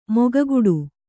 ♪ mogaguḍu